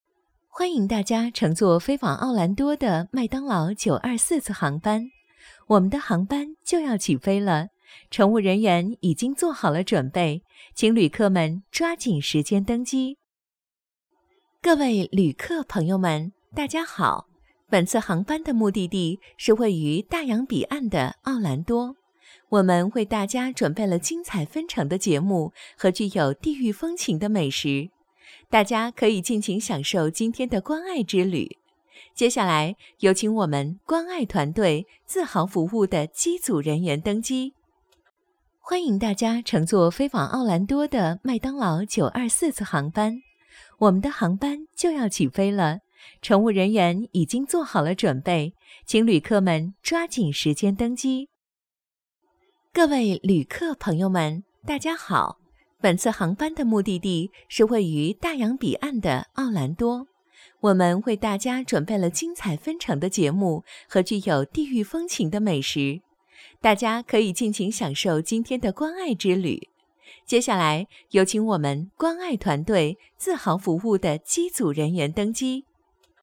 • 女S12 国语 女声 语音播报-航空航班语音-成熟 积极向上|时尚活力|亲切甜美